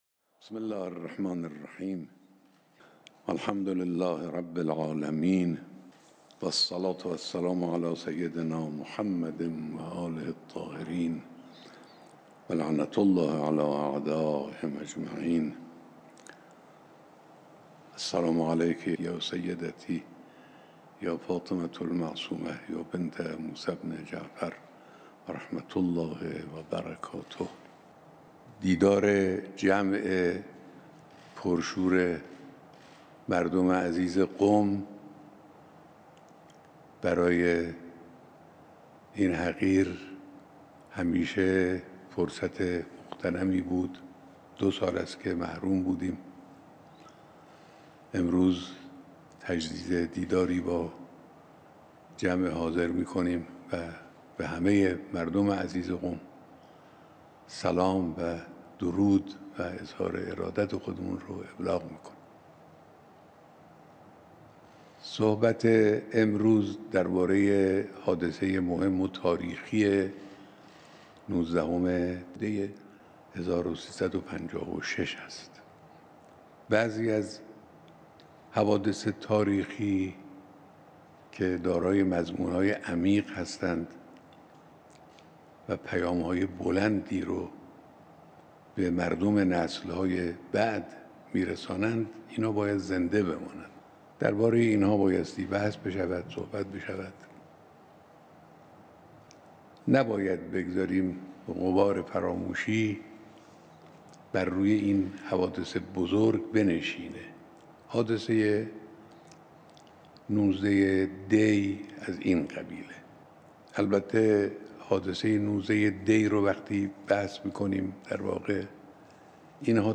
بیانات در دیدار تصویری با جمعی از مردم قم